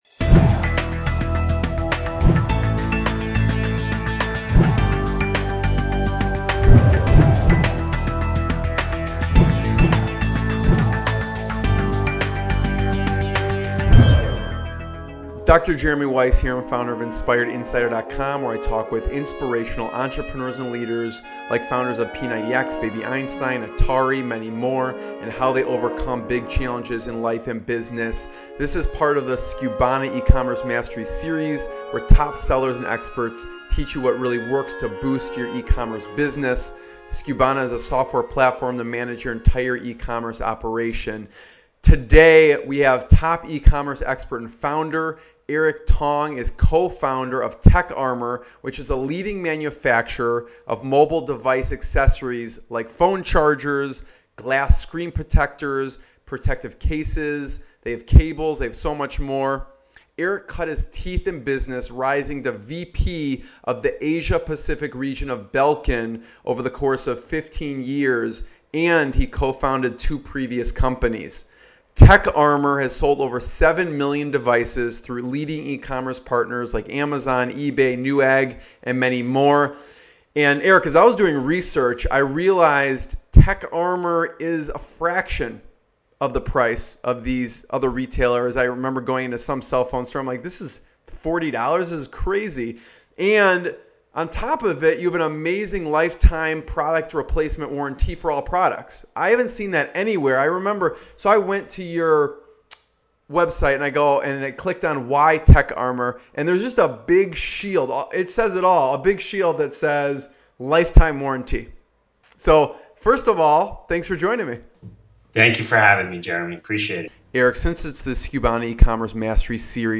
Inspirational Business Interviews with Successful Entrepreneurs and Founders